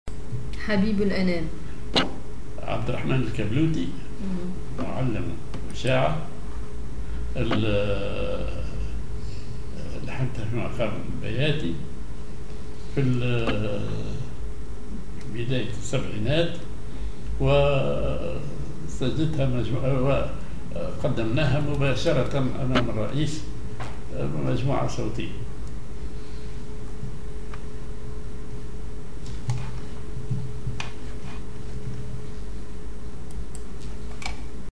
Maqam ar بياتي
genre أغنية